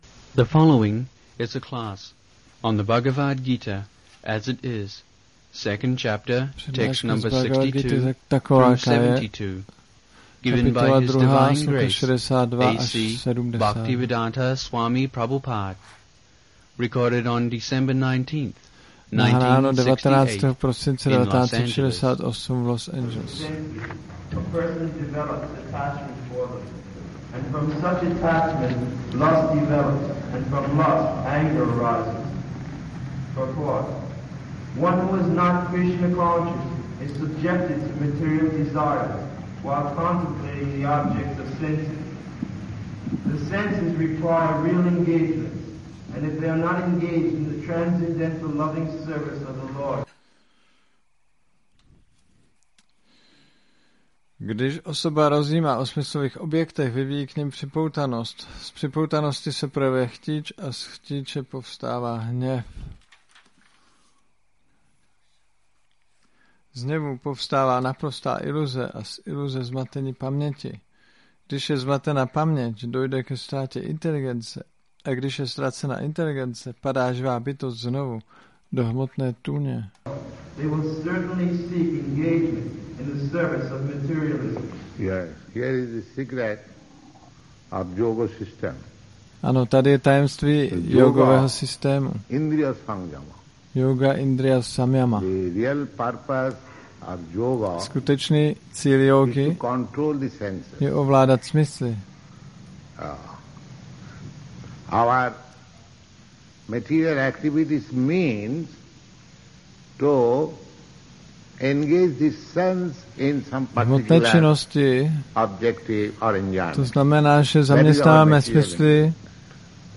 1968-12-19-ACPP Šríla Prabhupáda – Přednáška BG-2.62-72 Los Angeles